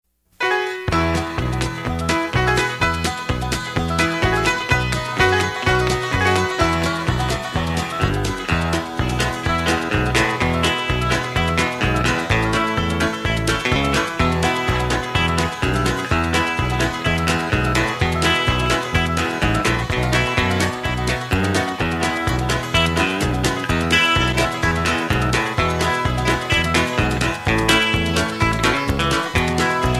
Patter